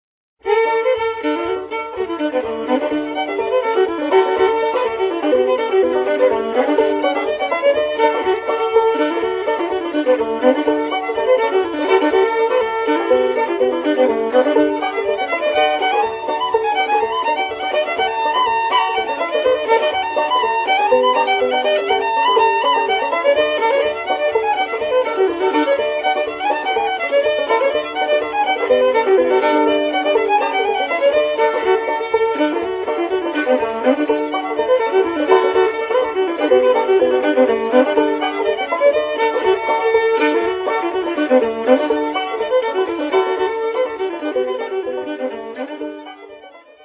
shows off his prowess on the lower strings